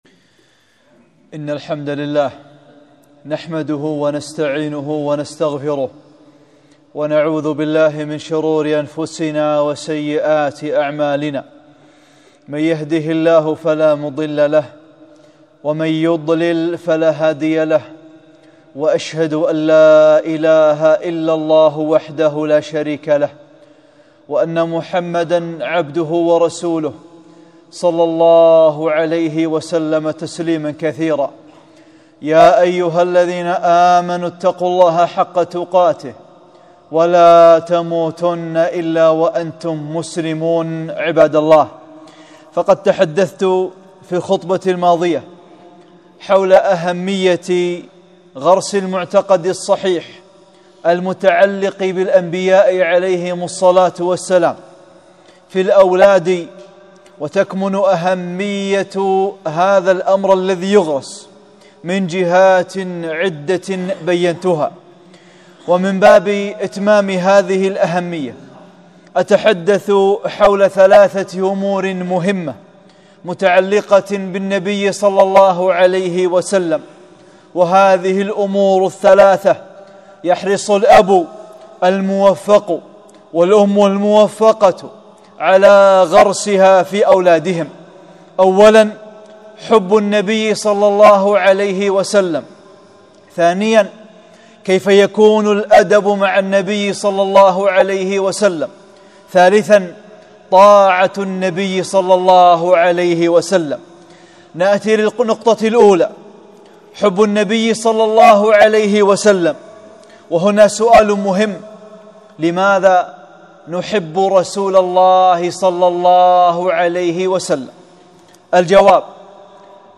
(34) خطبة - أهمية غرس الأب محبة النبي في الأولاد